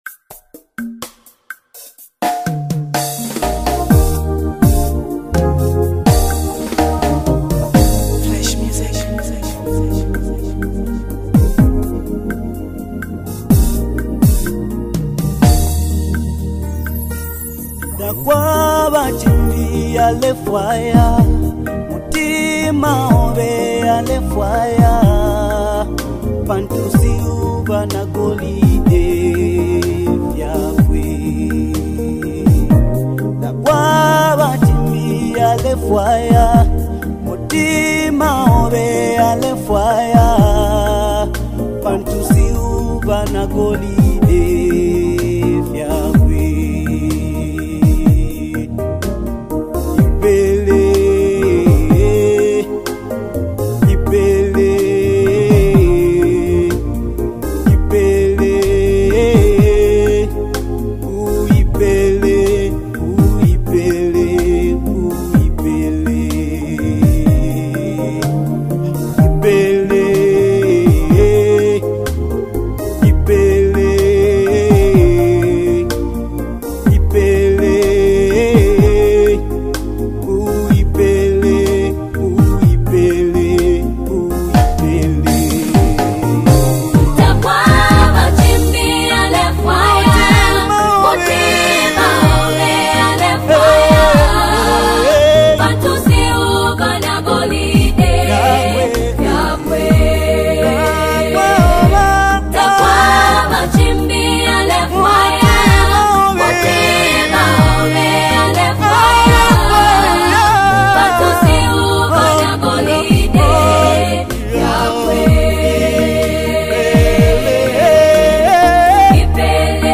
LATEST WORSHIP SONG 2024